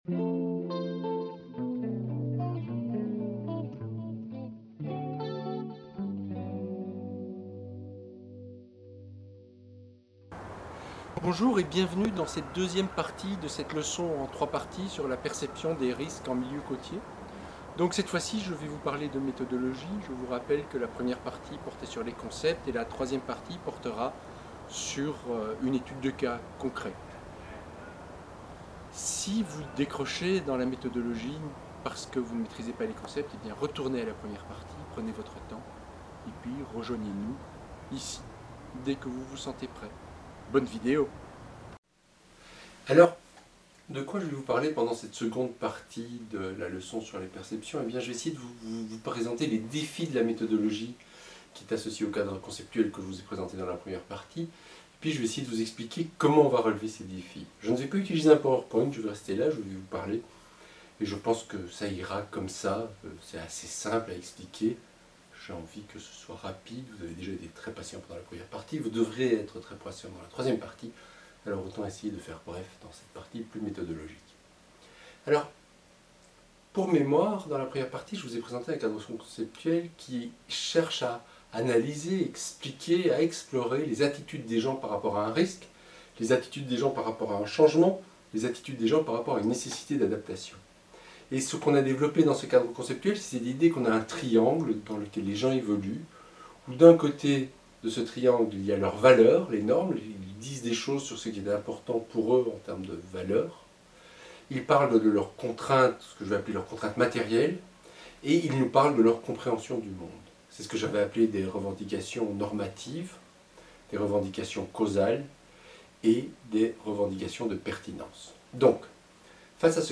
Il s'agit de la deuxième partie d'une leçon en trois parties sur l'analyse des perceptions dans un contexte d'adaptation côtière aux changements climatiques.